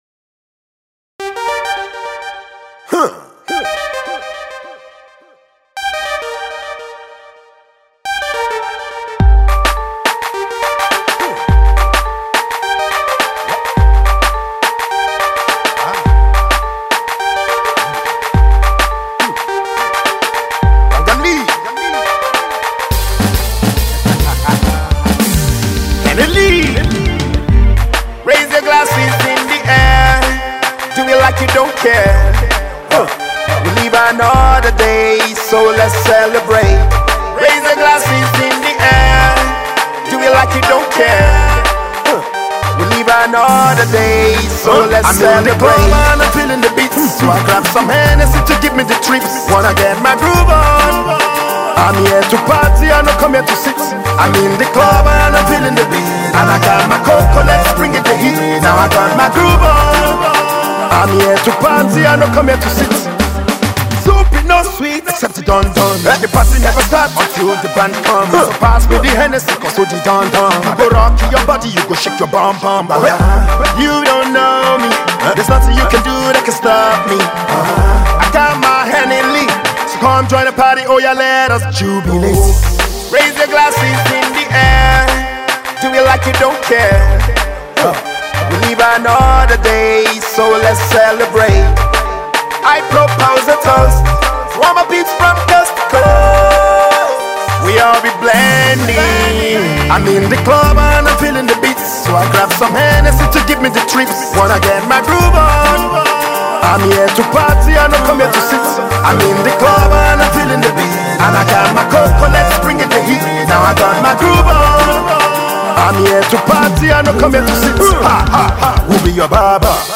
The mid tempo single is a celebratory feel good number.